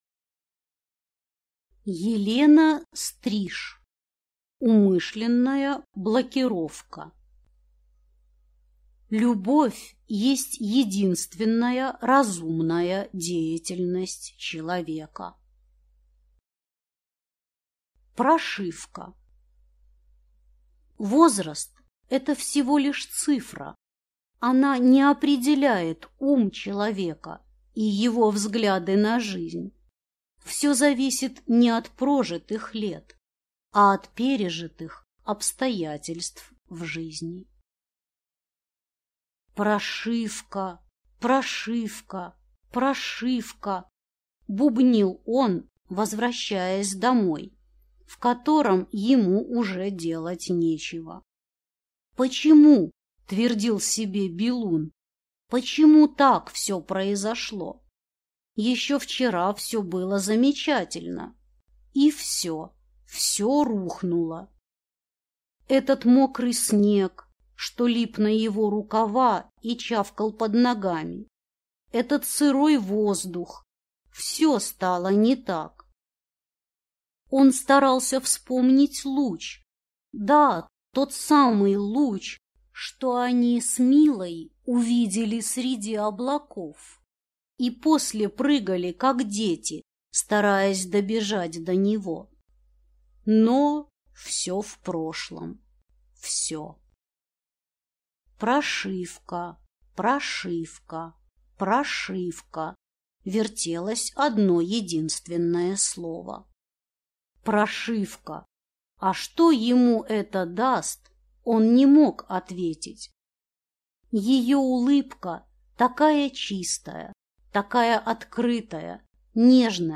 Аудиокнига Умышленная блокировка | Библиотека аудиокниг